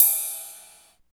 20 RIDE.wav